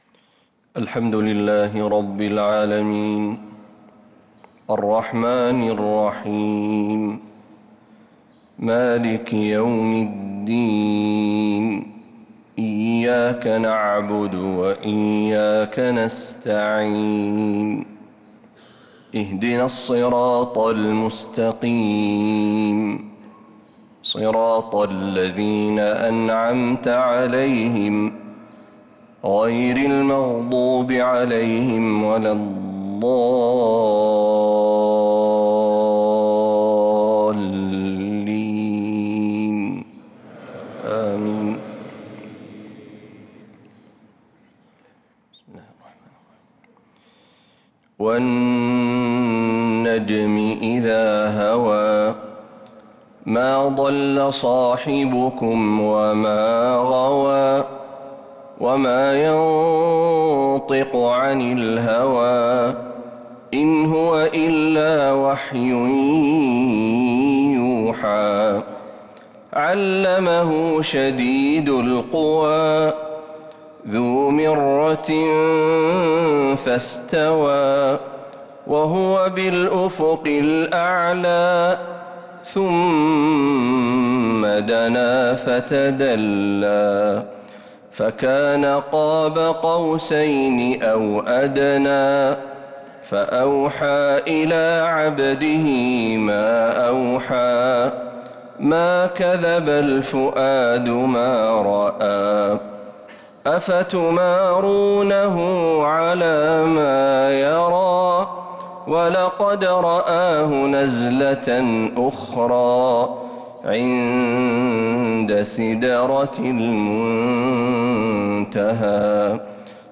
فجر الأحد 5-7-1446هـ من سورة النجم 1-55 | Fajr prayer from Surat an-Najm 5-1-2025 > 1446 🕌 > الفروض - تلاوات الحرمين